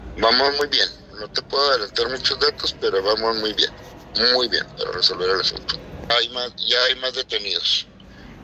AUDIO: CÉSAR JÁUREGUI MORENO, FISCAL GENERAL DEL ESTADO (FGE)